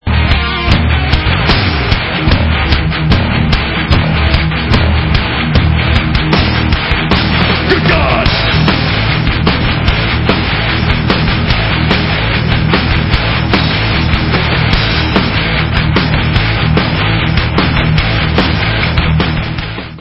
live
sledovat novinky v oddělení Heavy Metal